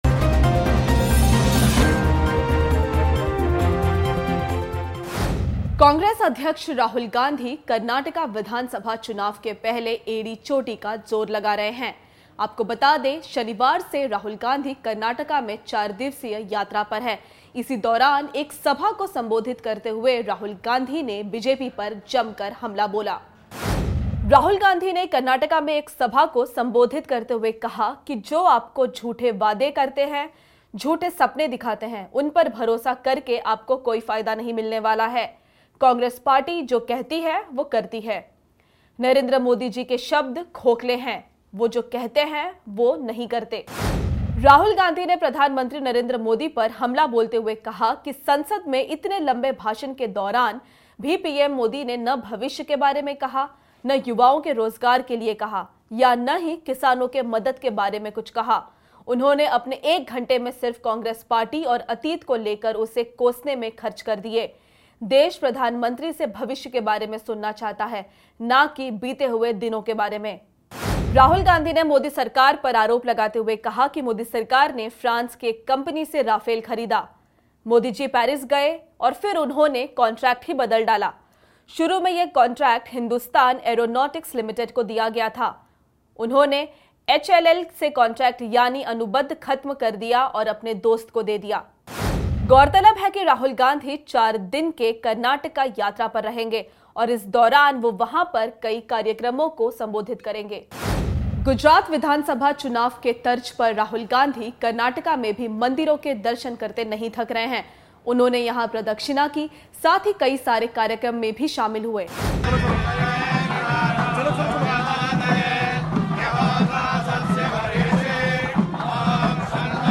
News Report / गुजरात की तरह कर्नाटक में भी राहुल गाँधी का जारी है 'टेम्पल रन'